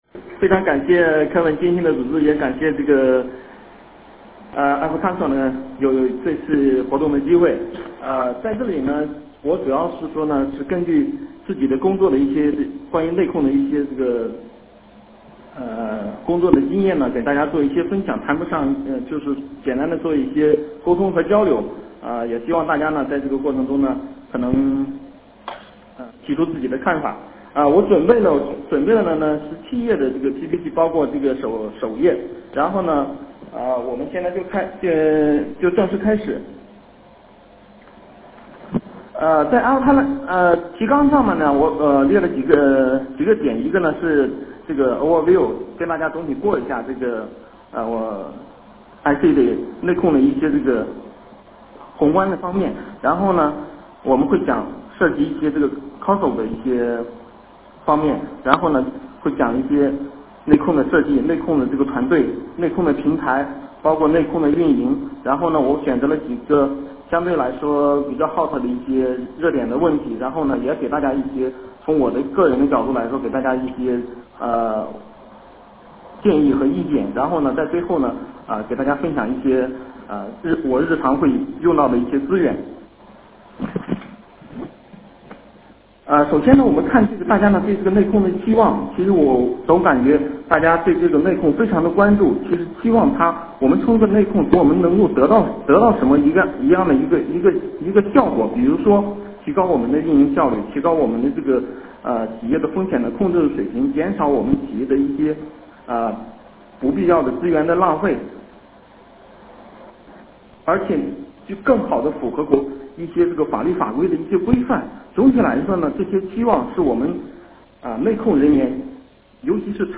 电话会议
主持人致辞
Q&A环节